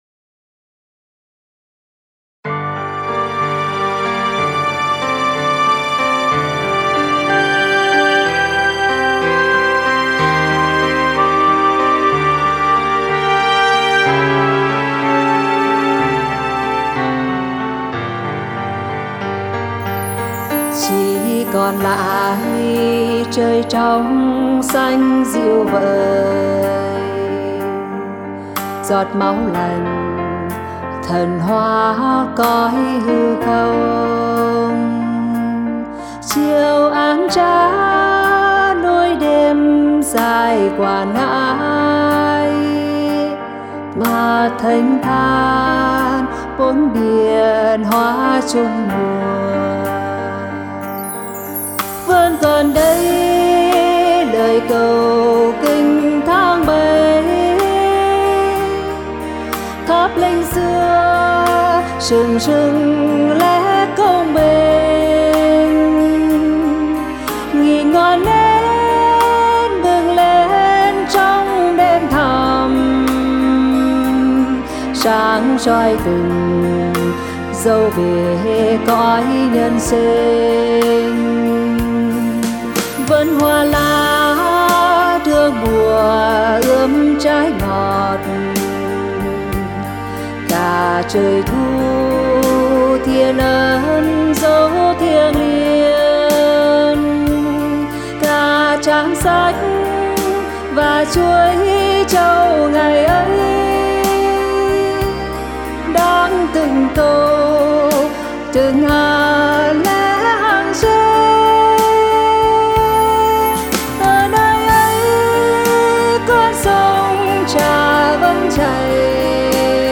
•   Vocal  03.